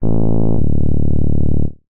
Added sound notification for login failure
wrong.ogg